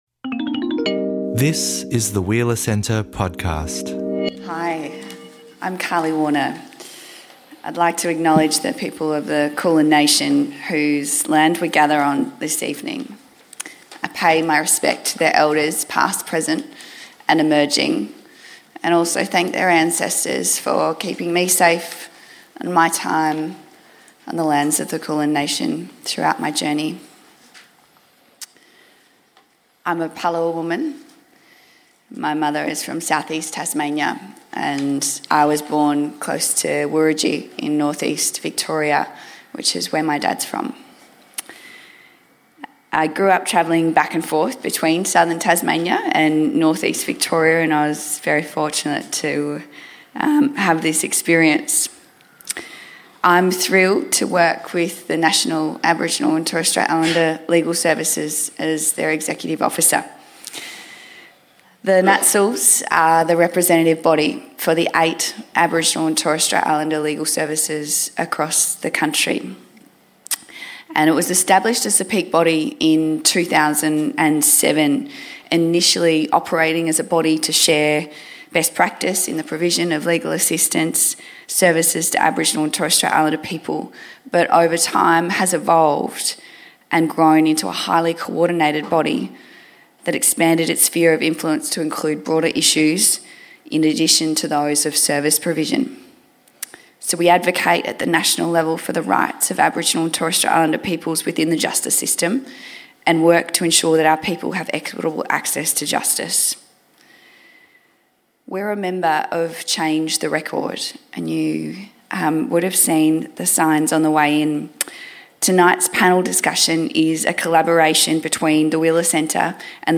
At this discussion, our panelists explore the connection between over-imprisonment of Aboriginal and Torres Strait Islander children and over-imprisonment of the Indigenous adult population. What are effective prevention and diversion strategies for young people – and what roles could NGOs, families and communities play?